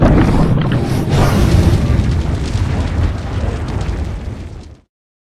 firebreath1.ogg